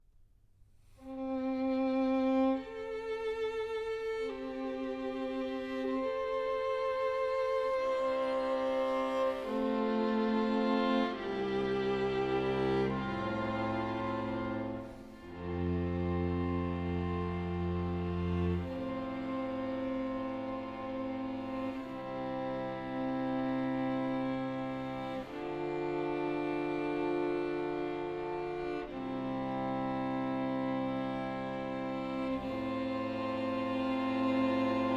Classical Chamber Music
Жанр: Классика